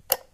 switch35.wav